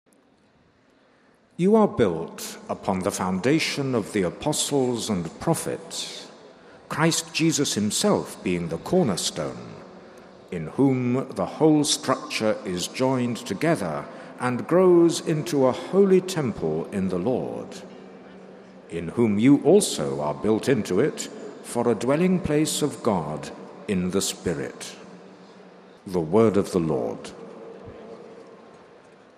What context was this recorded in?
The general audience of June 26th was held in the open, in Rome’s St. Peter’s Square. It began with aides reading a passage from the First Letter of St. Paul to the Ephesians in several languages.